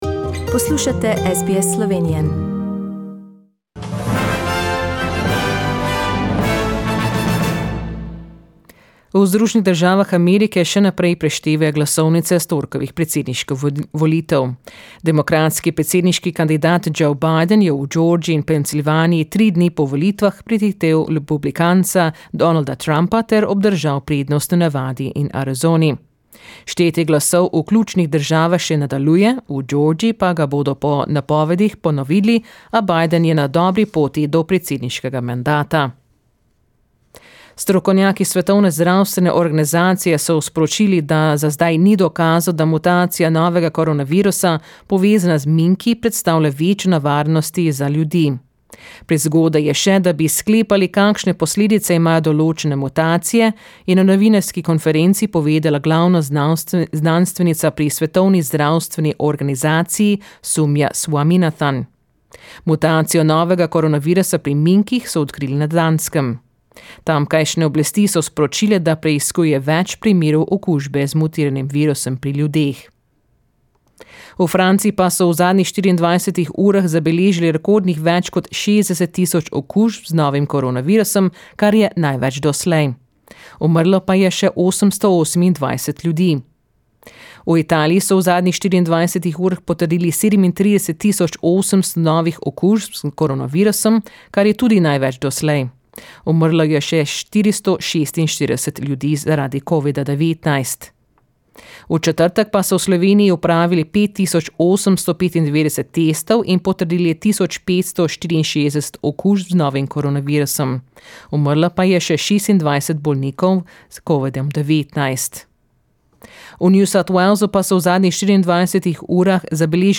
Poročila Radia SBS v slovenščini 7. novembra